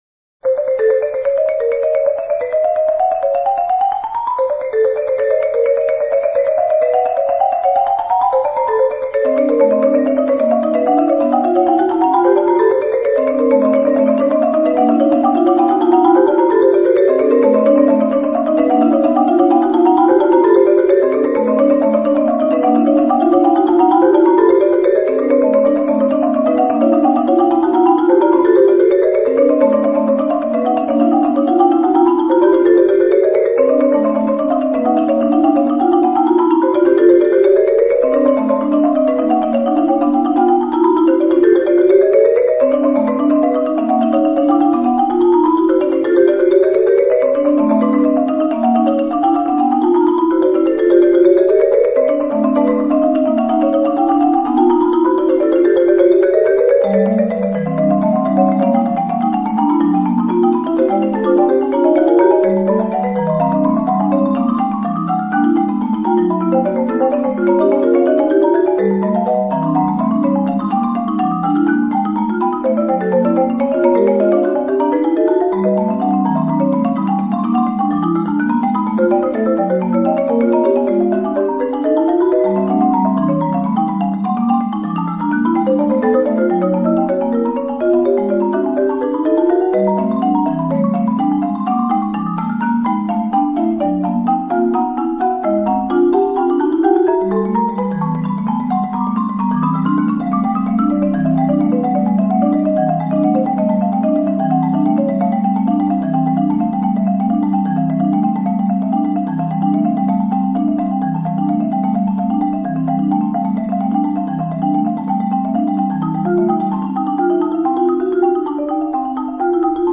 Maraton soudobé hudby 2004